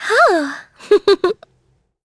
Isaiah-Vox_Happy3.wav